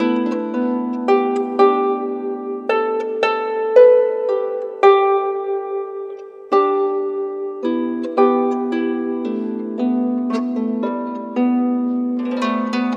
Harp06_111_G.wav